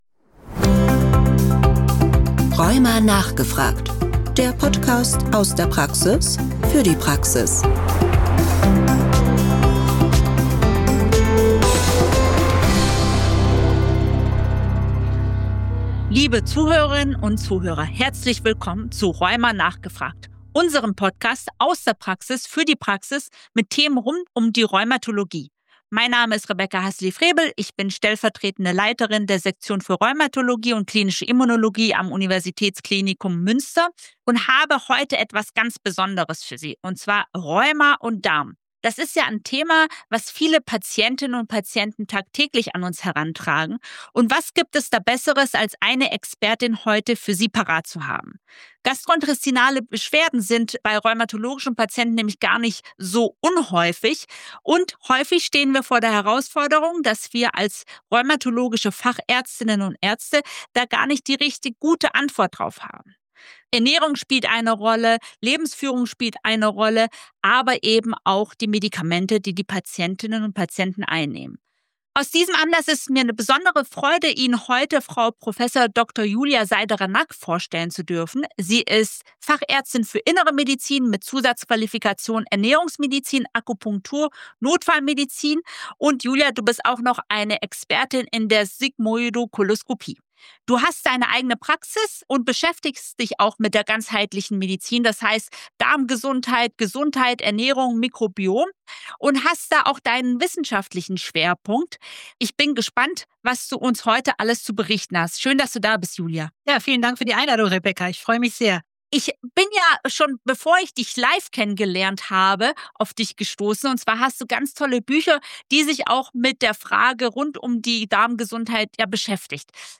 Im Gespräch erklären die beiden, wie CED, Reizdarm, Darmbarriere, Mikrobiom, Medikamente und Hormone entzündlich-rheumatische Beschwerden beeinflussen können. Sie beleuchten diagnostische, interdisziplinäre und ernährungstherapeutische Aspekte und geben praktische und synergistische Tipps für die Versorgung.